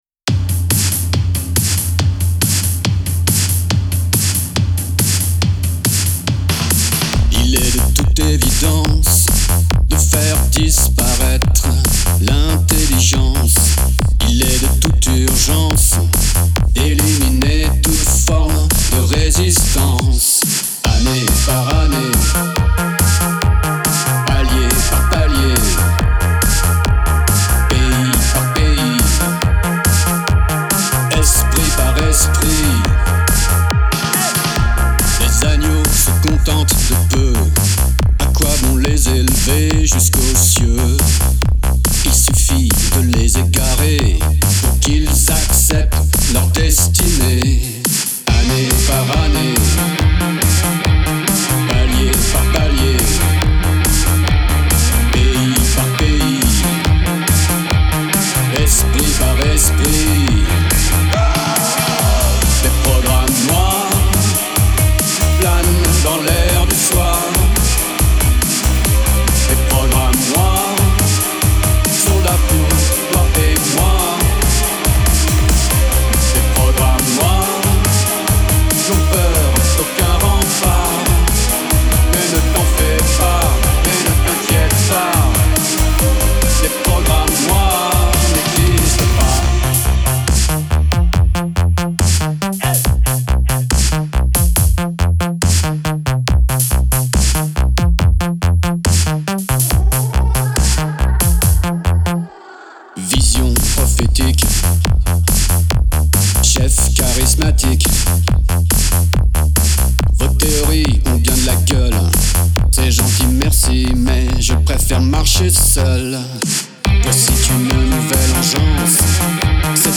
Un projet avec un chanteur/compositeur datant 2015/2020 qui n'a jamais vu le jour. Je suis à la guitare/bass, co-compo, arrangement et mix.